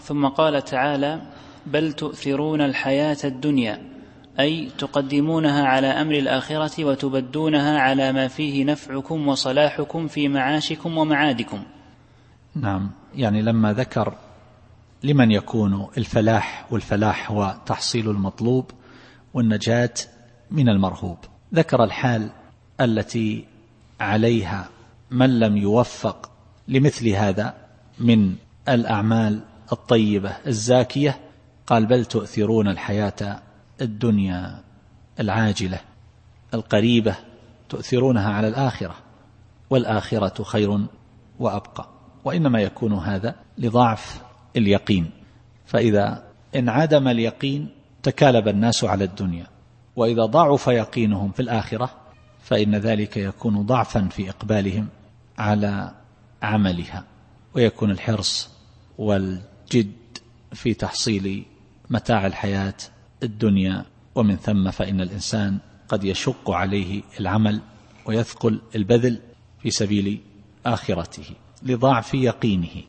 التفسير الصوتي [الأعلى / 16]